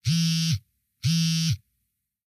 身近な生活音系の効果音がダウンロードできます。
携帯スマホのバイブ音5